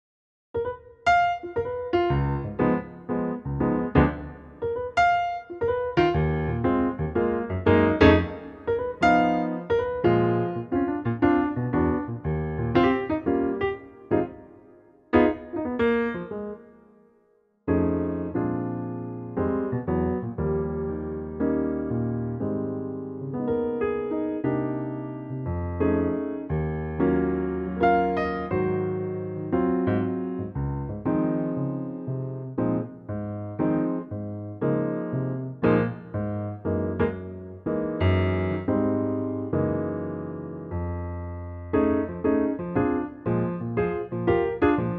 Unique Backing Tracks
key - Ab - vocal range - Eb to F
Piano only arrangement